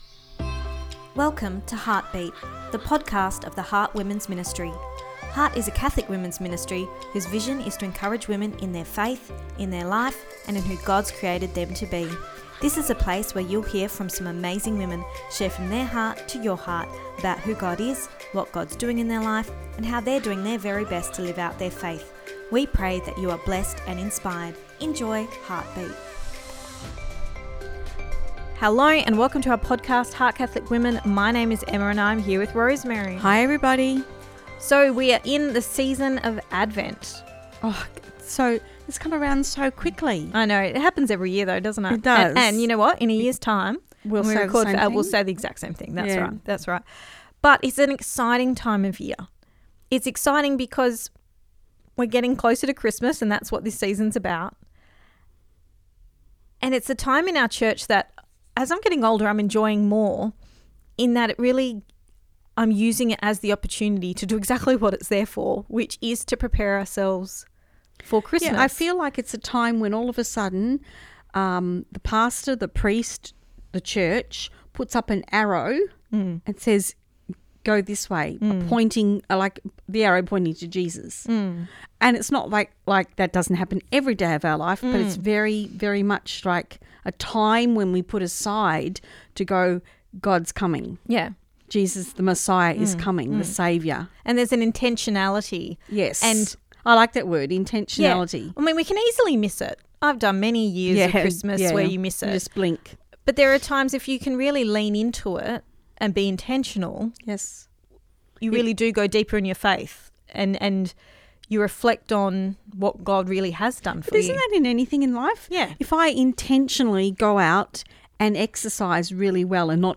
Ep225 Pt2 (Our Chat) – Advent: A Time of Hope